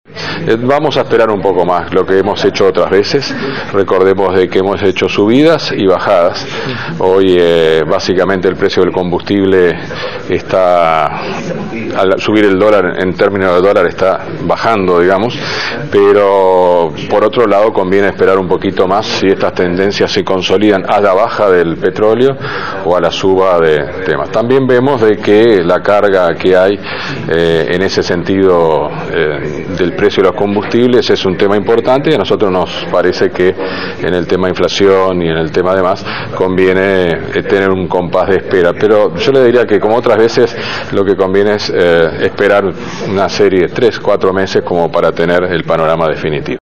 En declaraciones a la prensa el jerarca se refirió a la paramétrica.